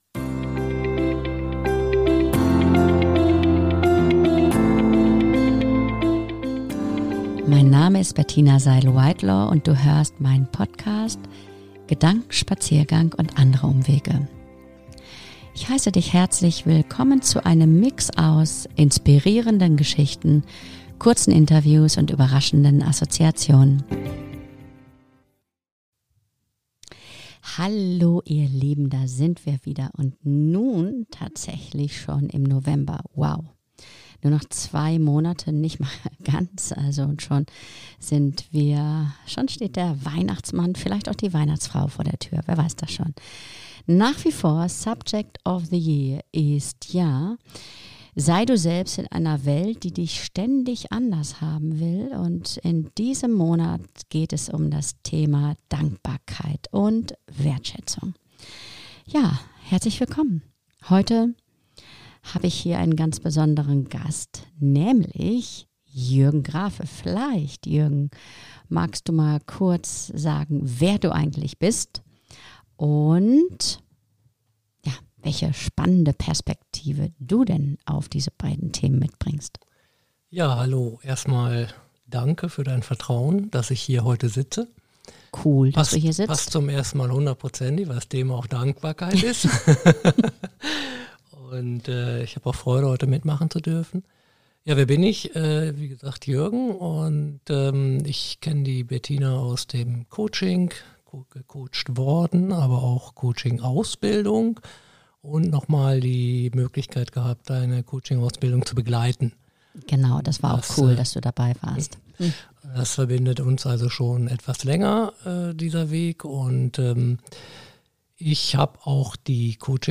Sie diskutieren, wie Dankbarkeit als Multiplikator wirkt und mehr Positives ins Leben bringt. Die beiden bieten praktische Tipps, um in herausfordernden Zeiten den Zugang zur Dankbarkeit wiederzufinden.